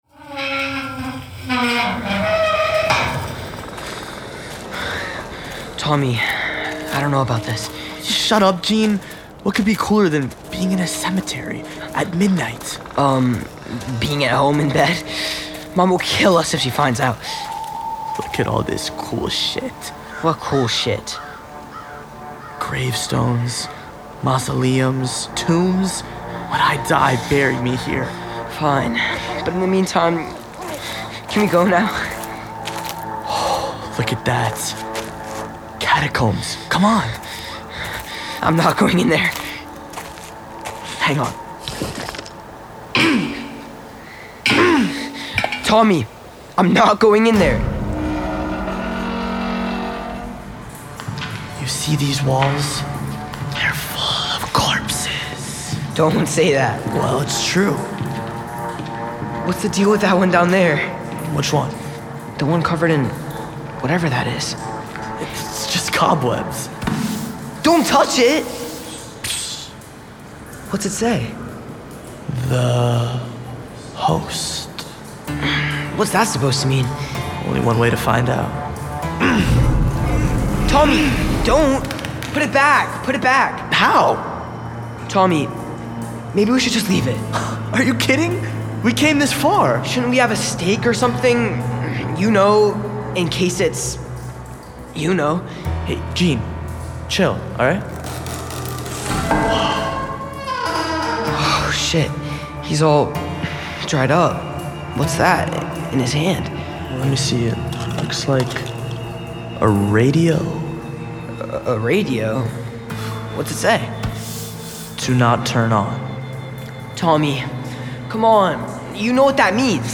Glass Eye Pix Presents TALES FROM BEYOND THE PALE Season 6 Teaser: “The Host" Tommy and Gene uncover more than they bargain for when strolling through a graveyard at midnight. Writer and Score